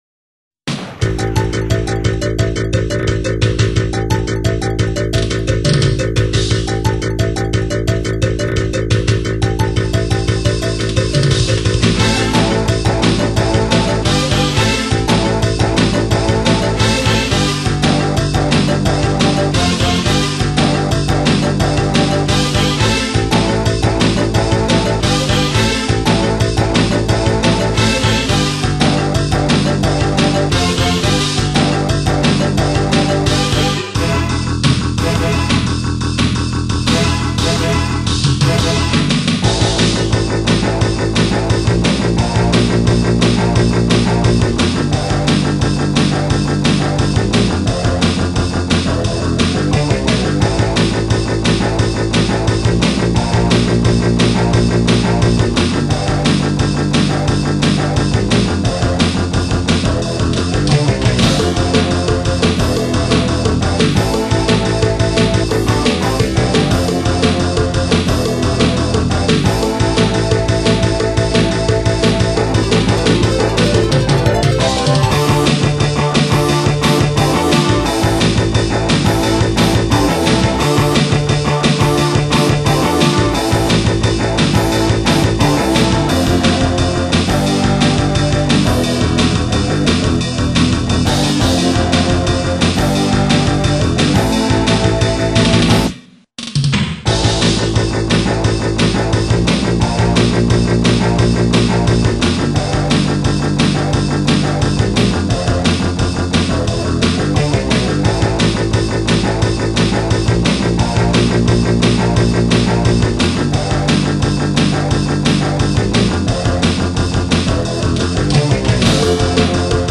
음질이 좀 구리구리해도 곡은 좋네요.